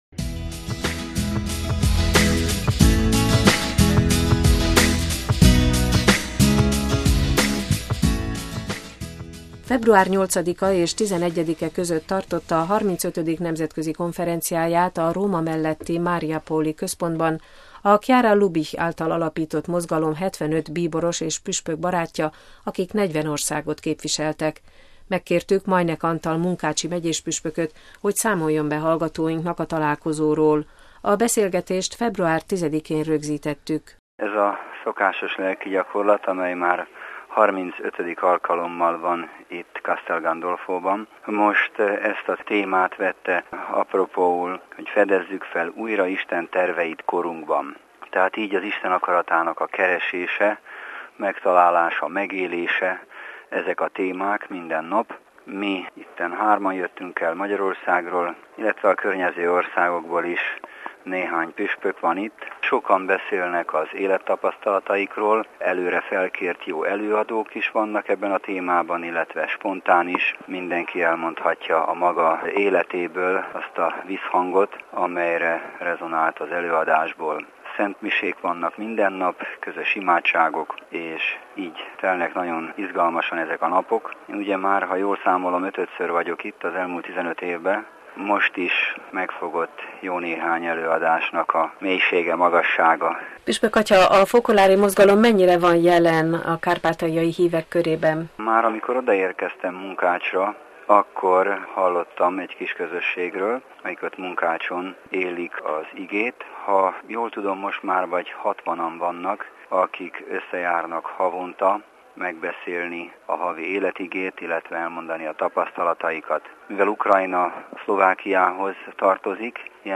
A Fokoláre mozgalom karizmájának középpontjában az elhagyott Jézus áll – interjú Majnek Antal munkácsi megyéspüspökkel